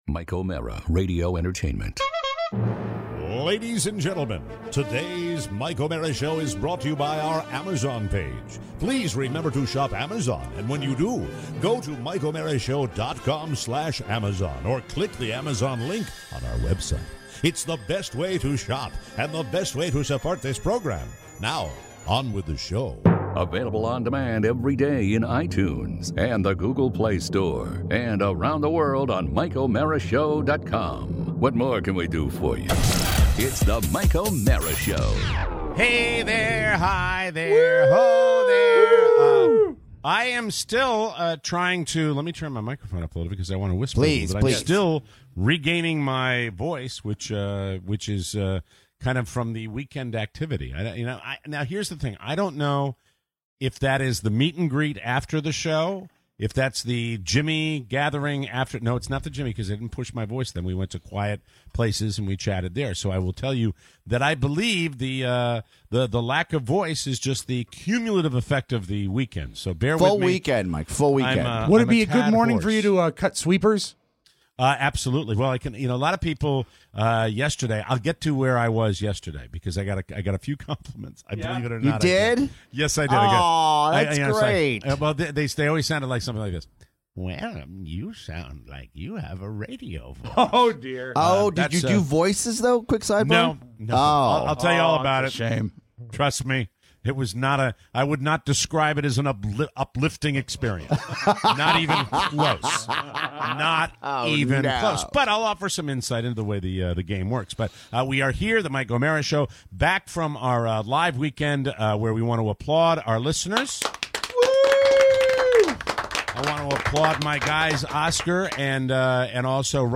Back, live, in-studio… after our live show.